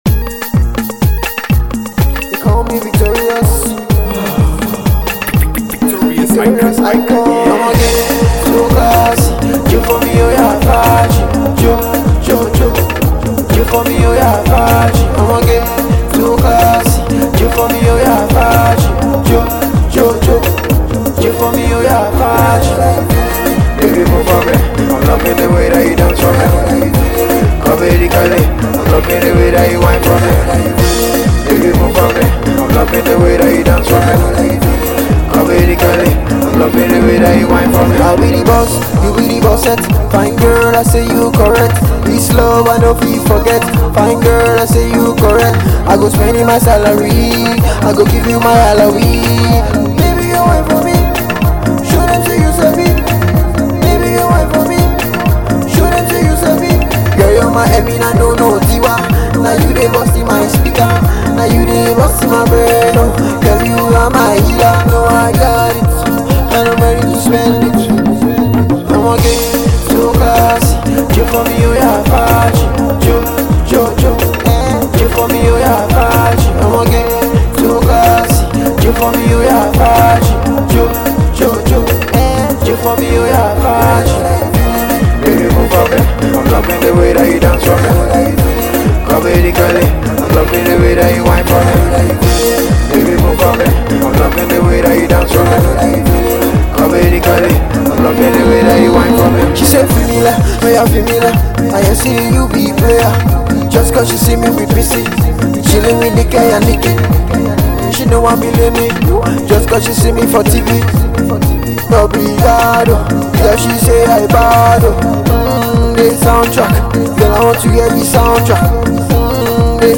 and its a Smooth song